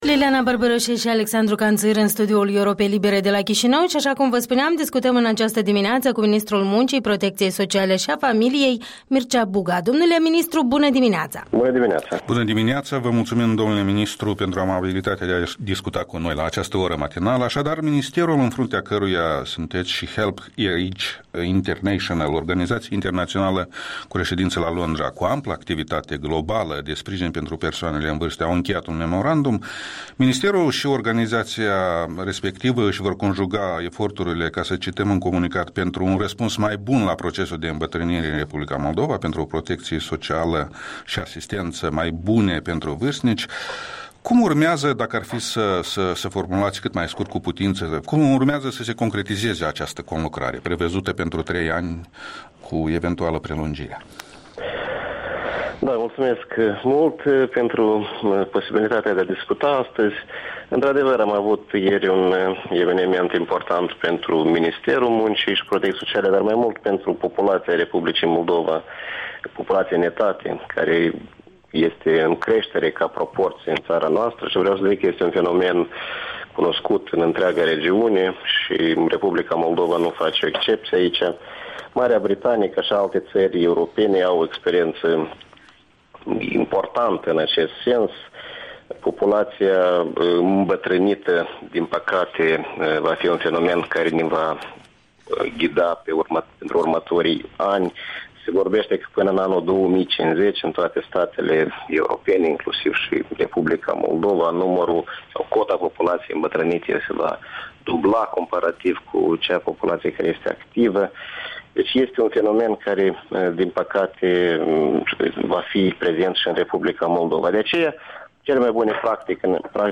Interviul dimineții cu Ministrul Muncii, Protecției Sociale și Familiei.
Interviul dimineții cu ministrul muncii Mircea Buga